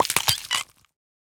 Minecraft Version Minecraft Version 1.21.5 Latest Release | Latest Snapshot 1.21.5 / assets / minecraft / sounds / mob / turtle / baby / egg_hatched2.ogg Compare With Compare With Latest Release | Latest Snapshot
egg_hatched2.ogg